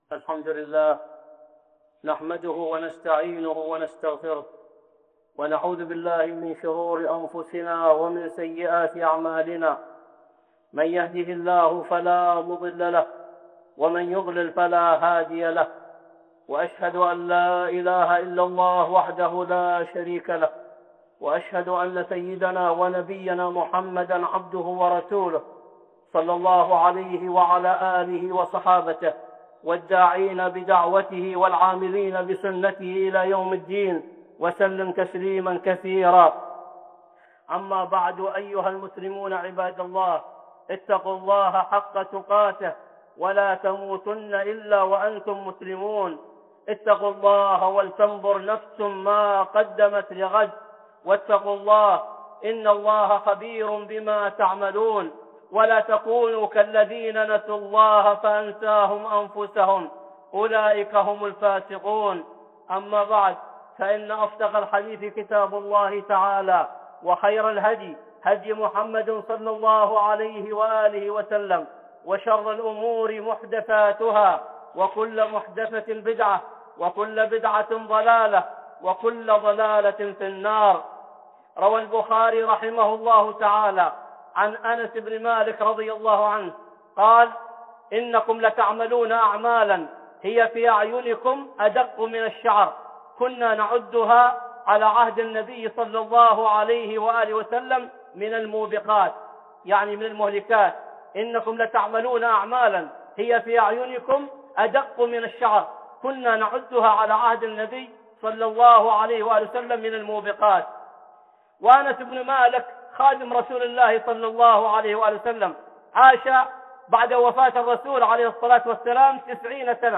(خطبة جمعة) ذنوب نراها ادق من الشعر 1
خطبة صوتية